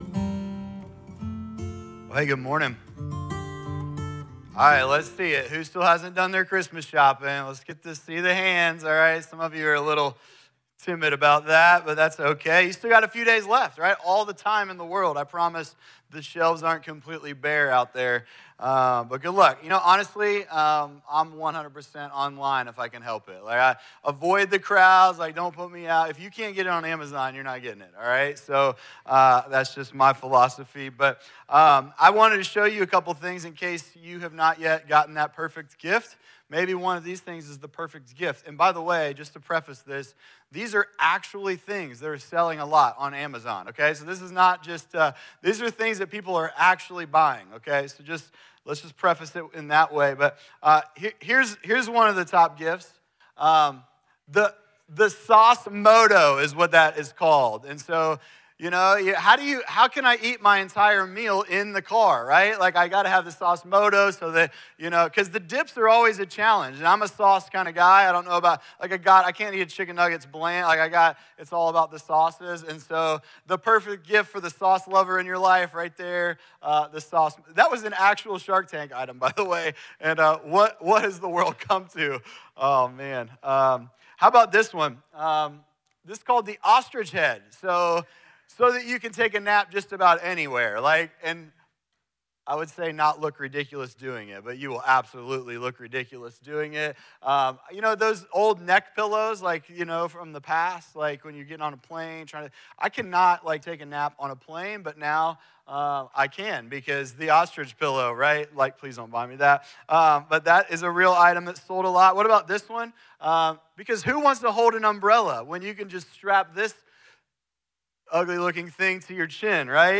Listen or watch on-demand to the weekly message.